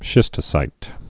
(shĭstə-sīt)